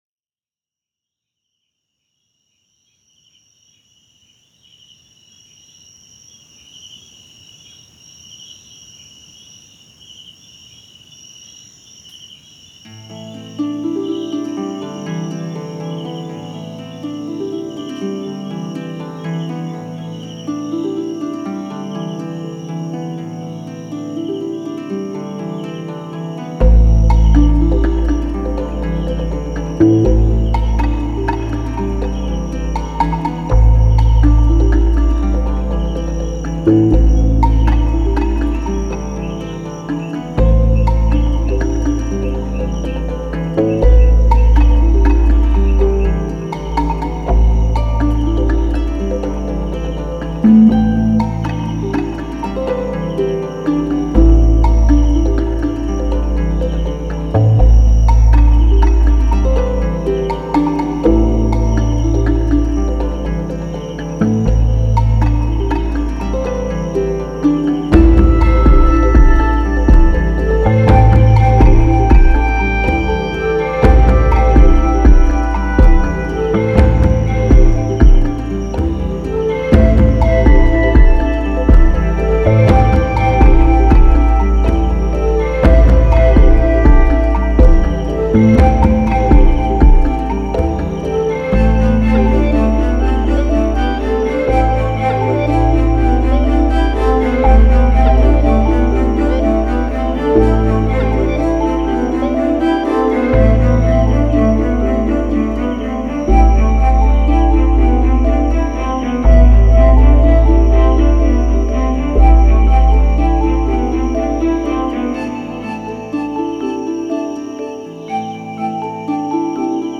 Music / Trance